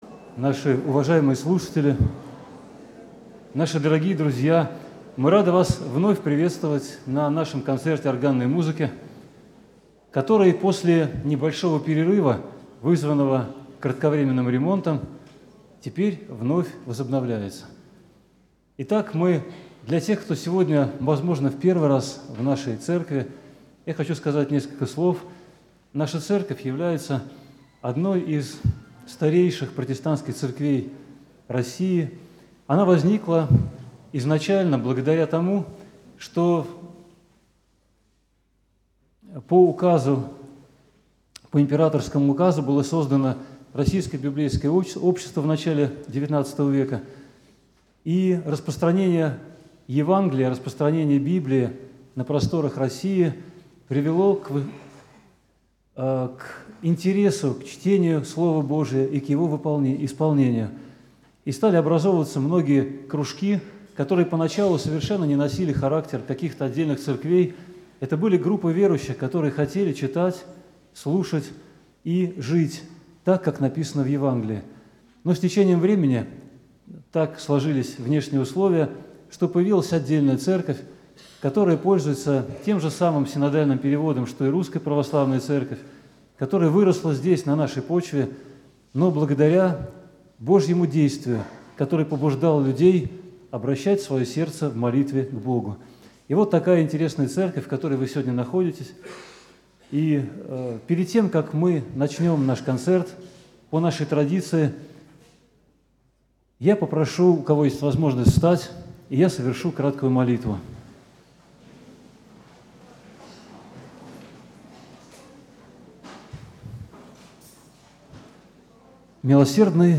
Вечер органной музыки